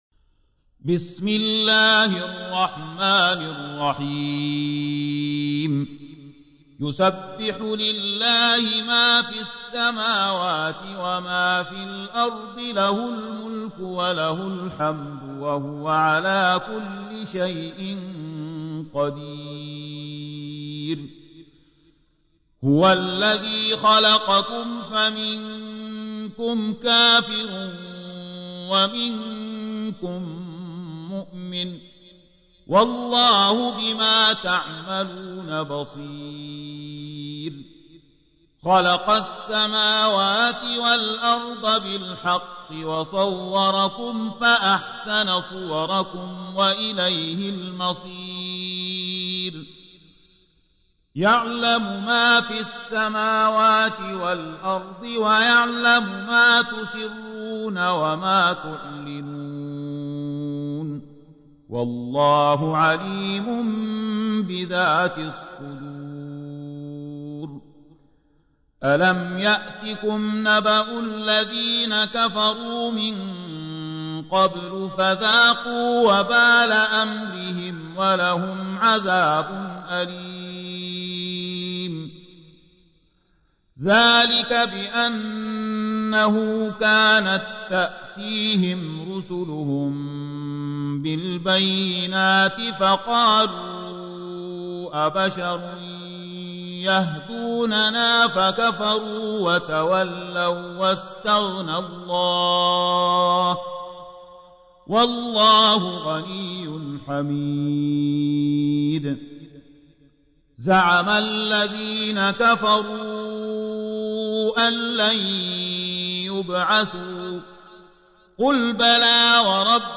تحميل : 64. سورة التغابن / القارئ زكي داغستاني / القرآن الكريم / موقع يا حسين